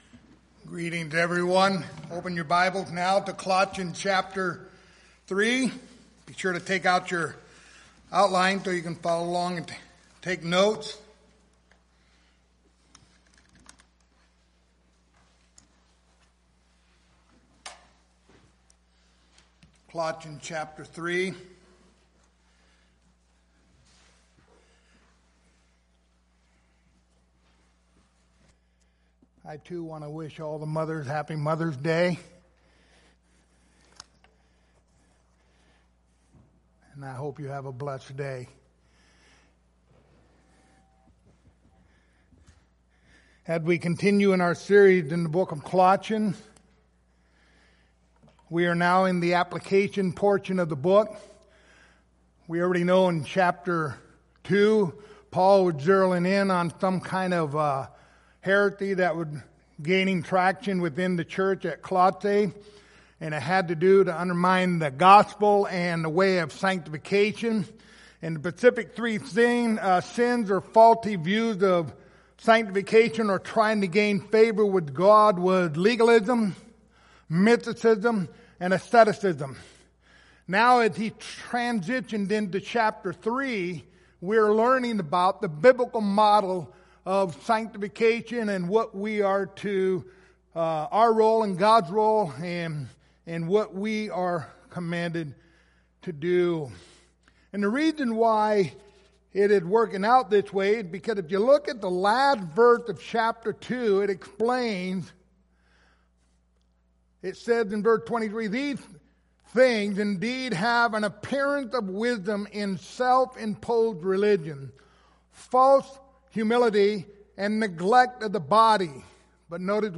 Passage: Colossians 3:8 Service Type: Sunday Morning